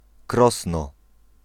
Krosno [ˈkrɔsnɔ]
Pl-Krosno.ogg.mp3